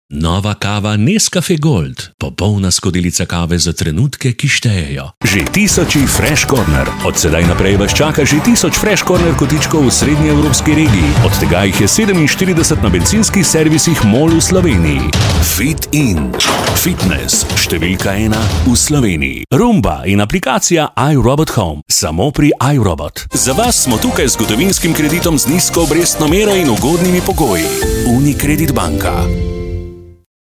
Vertrauenswürdig
Warm
Freundlich